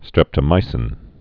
(strĕptə-mīsĭn)